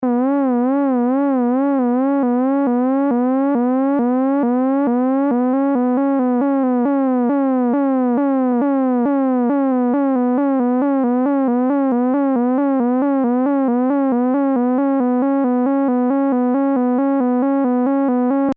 Demonstrates the various LFO waveform shapes.